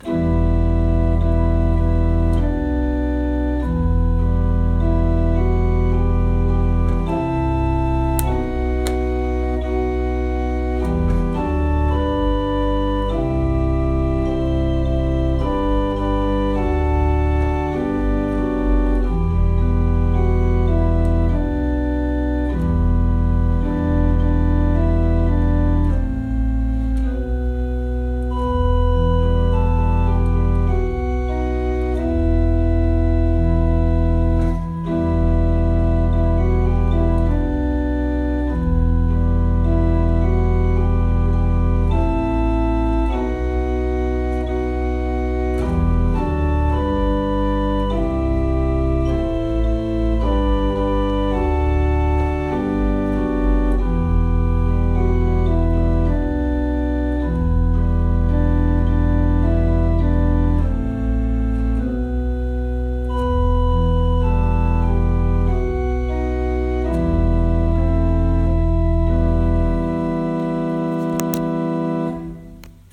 Orgel: Vorspiel